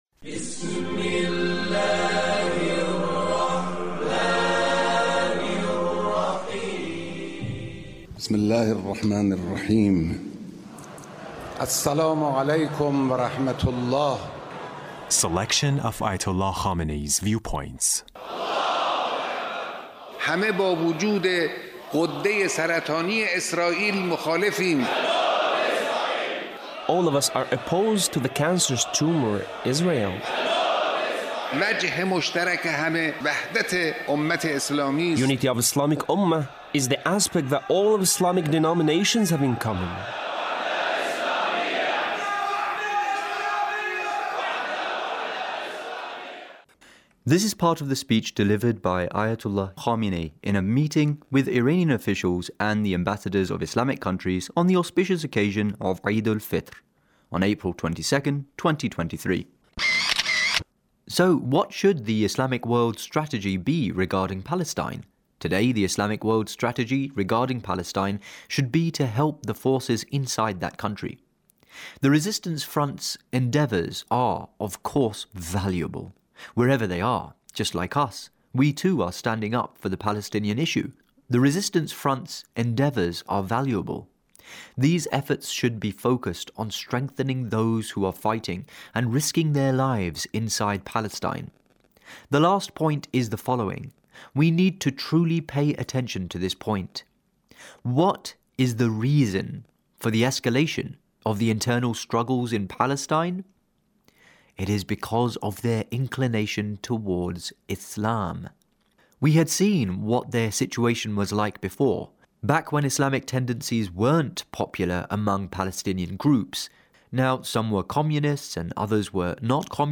Leader's Speech on Eid al Fitr